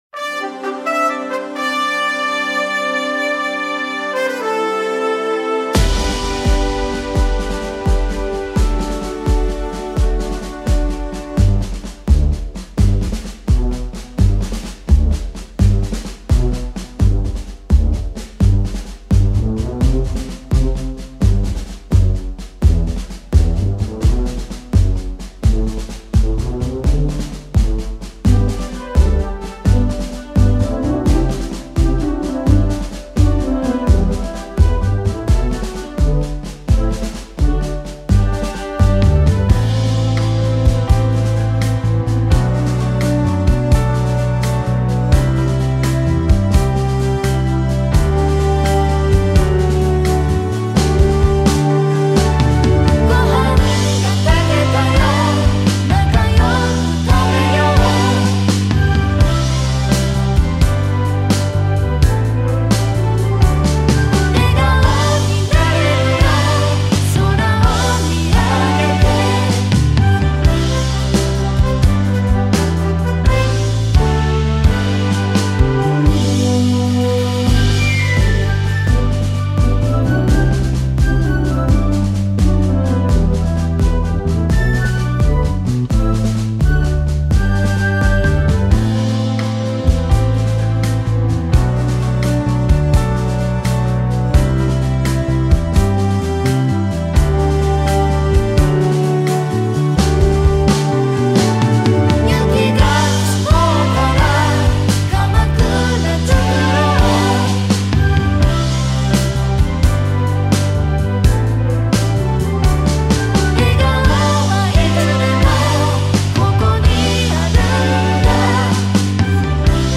カラオケ